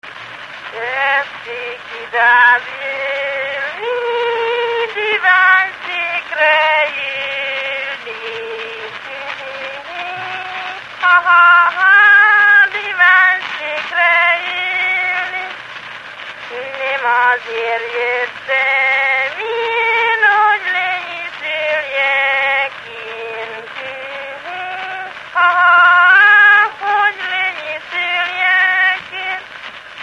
Dunántúl - Zala vm. - Szentgyörgyvölgy
Gyűjtő: Vikár Béla
Stílus: 8. Újszerű kisambitusú dallamok